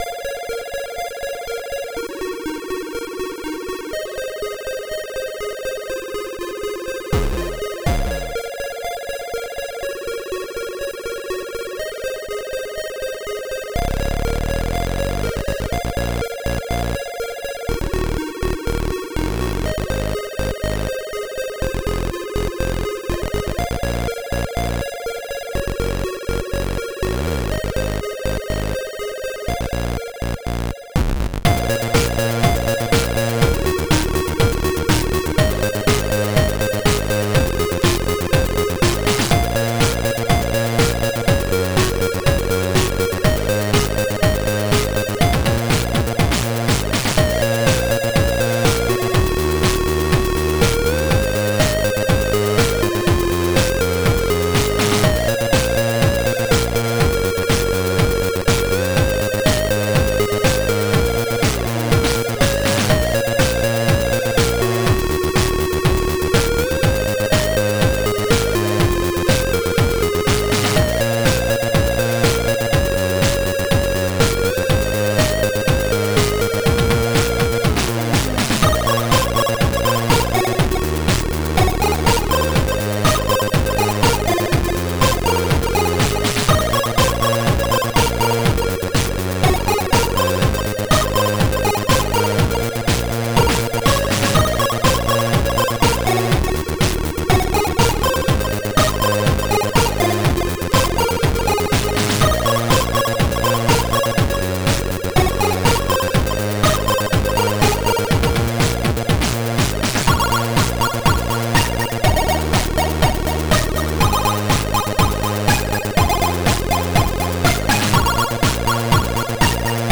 • An allocated channels for playing by default is ABC
• Звуковой чип AY-3-8912 / YM2149.